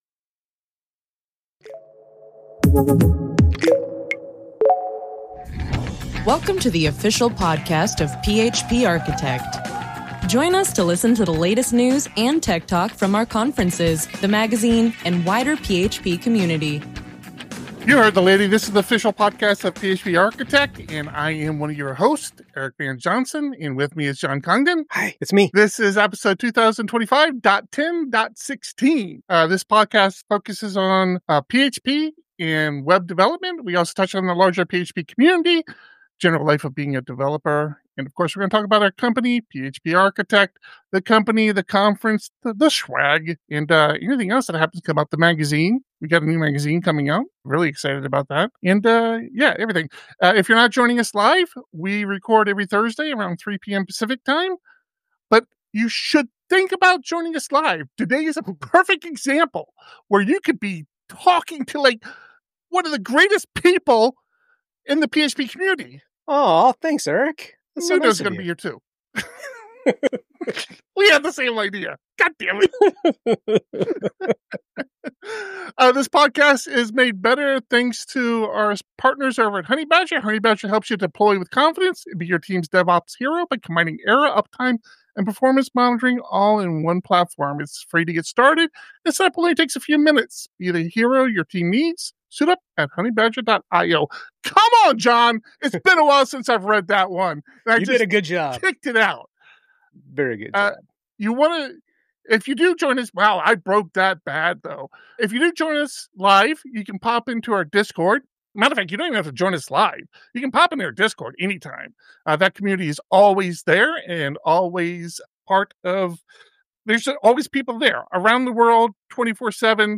In this special live episode of The PHP Podcast